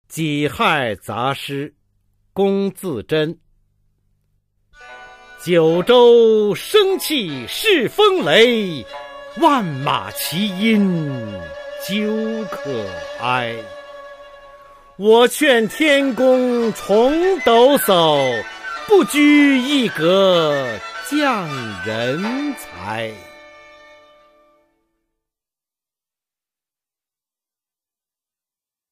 [清代诗词诵读]龚自珍-己亥杂诗（九州）(男) 配乐诗朗诵